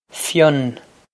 Fionn Fyun
This is an approximate phonetic pronunciation of the phrase.